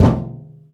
metal_drum_impact_thud_06.wav